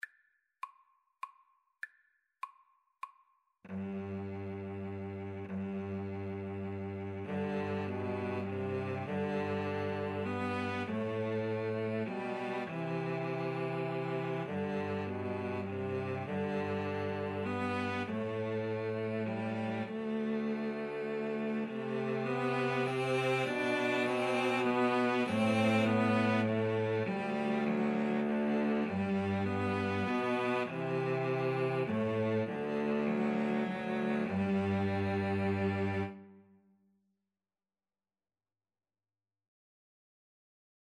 17th-century English folk song.
G major (Sounding Pitch) (View more G major Music for Cello Trio )
Moderato
Cello Trio  (View more Easy Cello Trio Music)